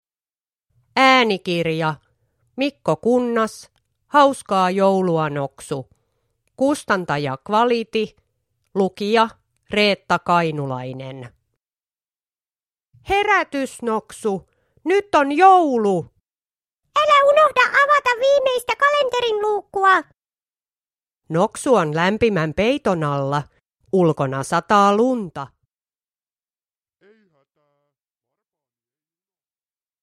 Hauskaa joulua, Noksu (ljudbok) av Mikko Kunnas